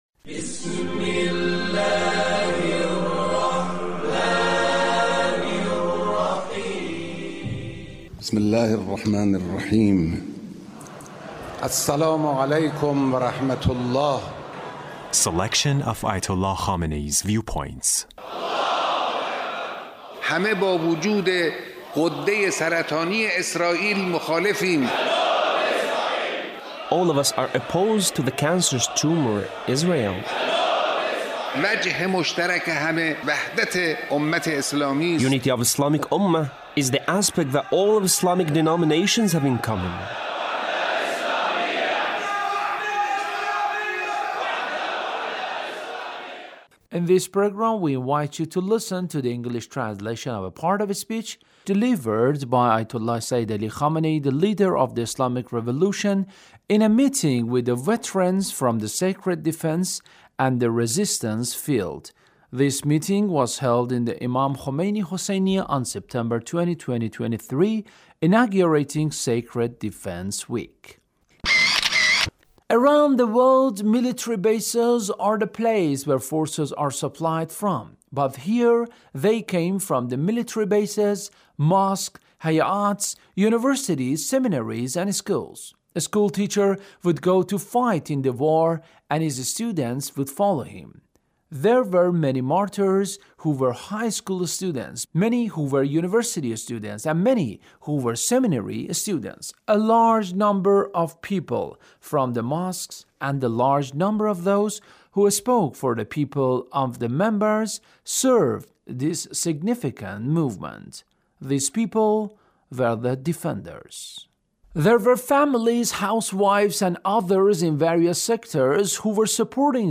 Leader's Speech on Sacred Defense